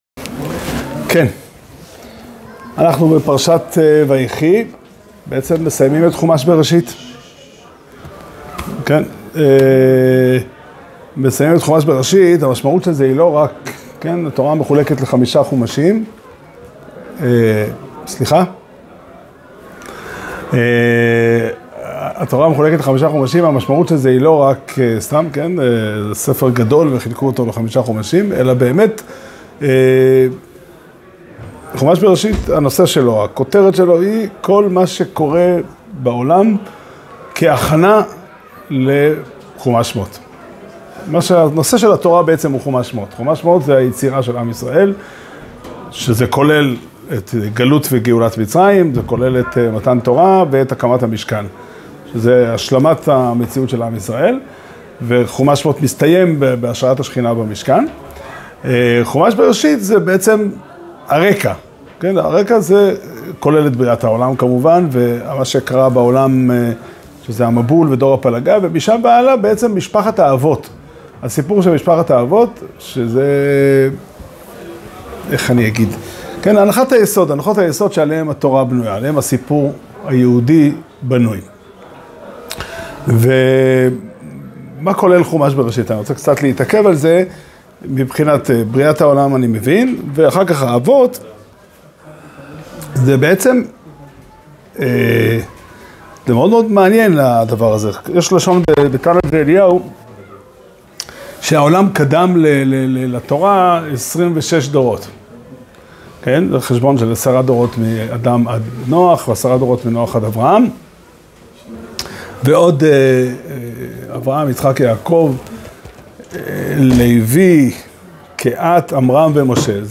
שיעור שנמסר בבית המדרש פתחי עולם בתאריך כ"ט כסלו תשפ"ה